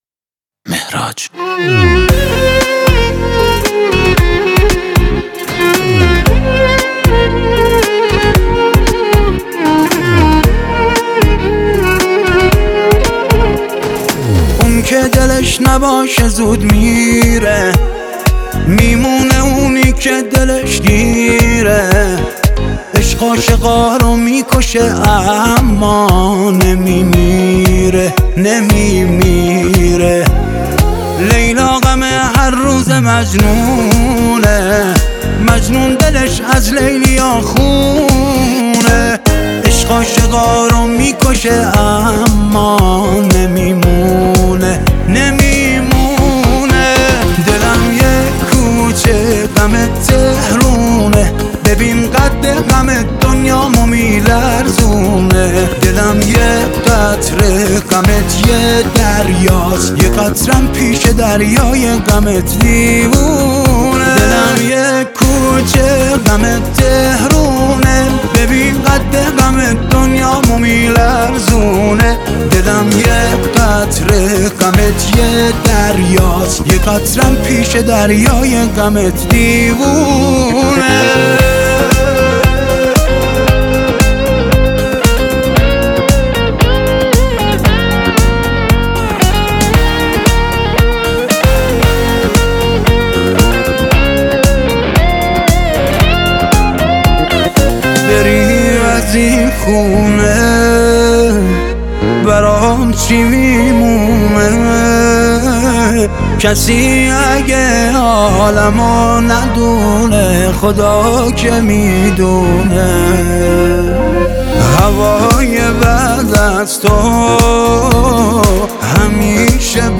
پاپ
آهنگ با صدای زن